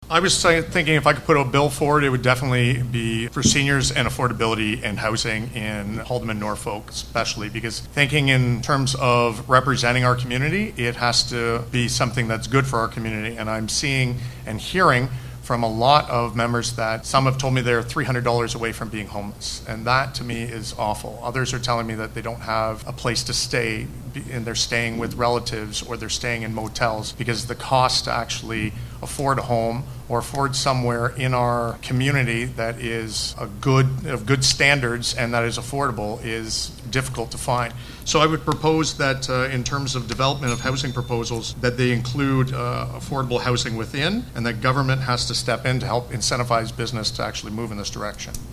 All-candidates debate, hosted by the Simcoe and District Chamber of Commerce